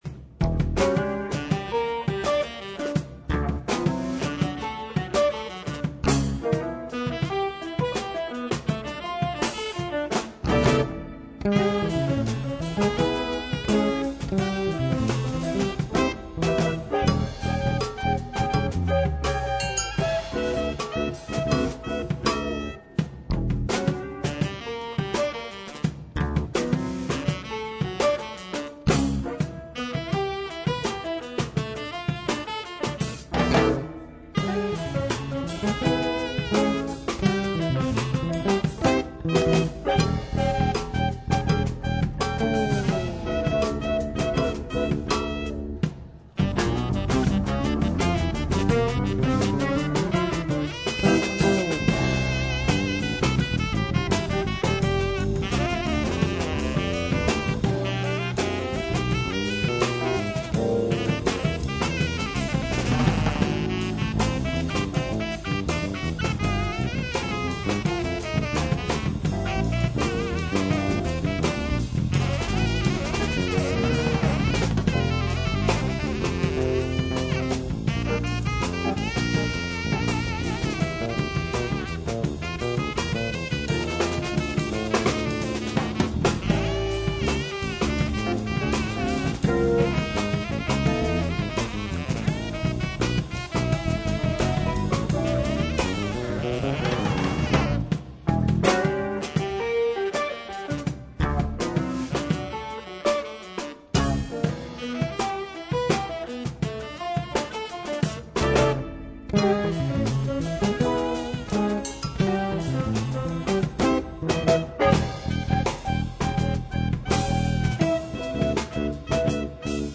Click here for a 2,892 kb mp3 of a live version from 1993.
medium funk tune